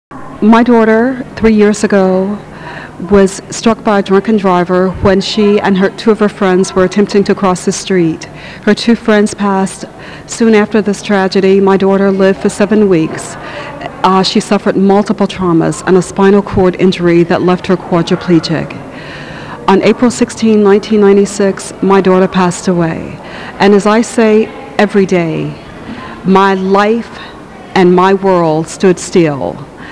North Carolina observes National Crime Victims Rights Week April 29, 1999 with a ceremony on the grounds of the State Capitol.